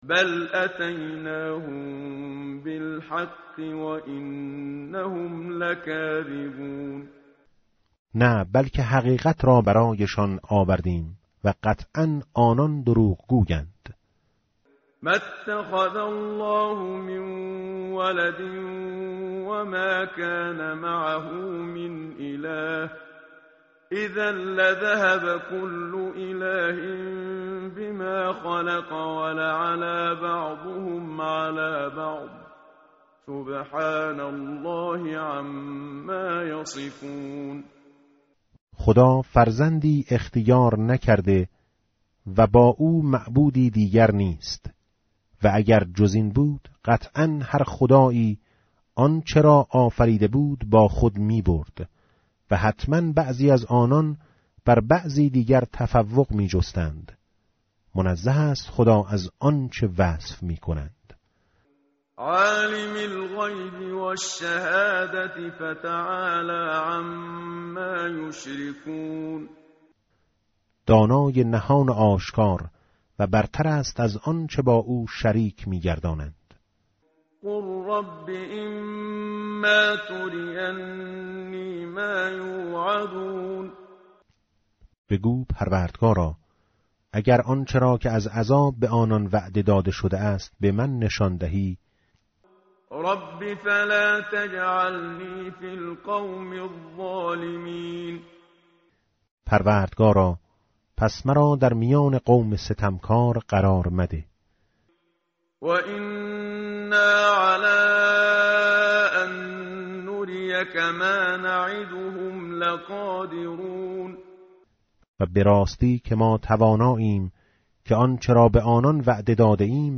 tartil_menshavi va tarjome_Page_348.mp3